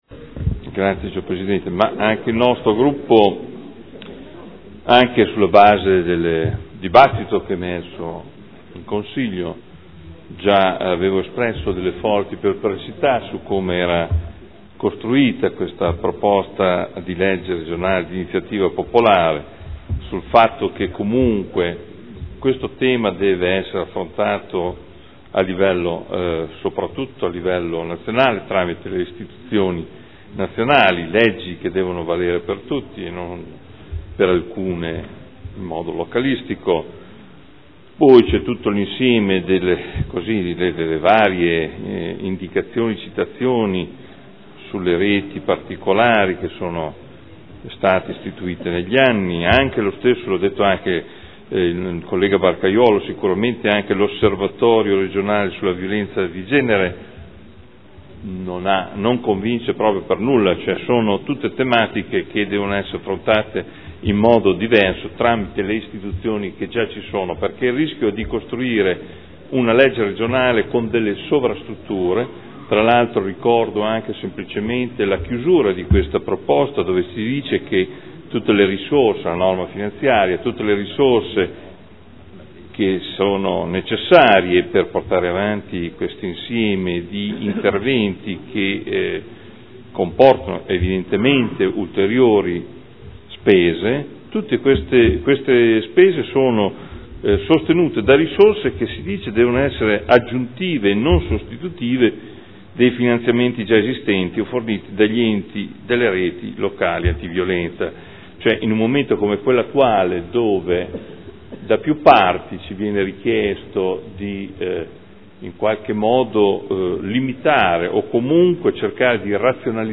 Seduta del 27/05/2013. Dichiarazione di voto.